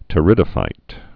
(tə-rĭdə-fīt, tĕrĭ-dō-)